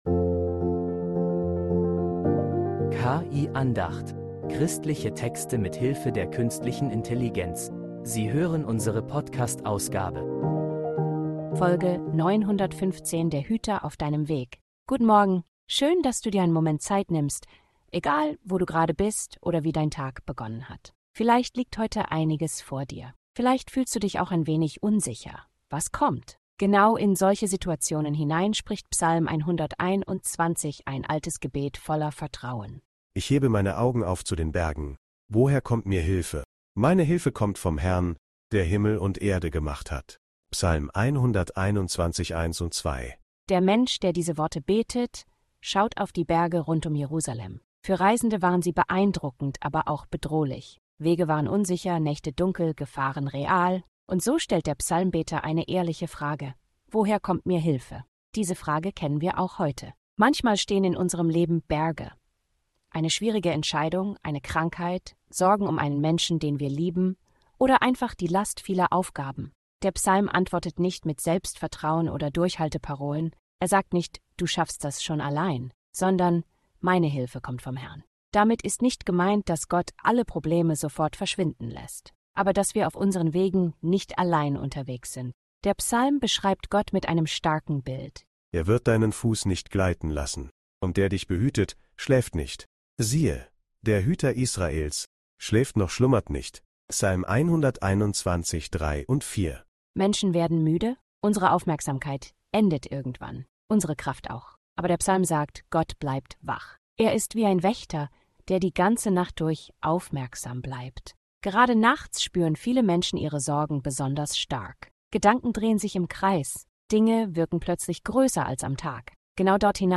Eine kurze Andacht über Vertrauen, Bewahrung und einen Gott, der nicht schläft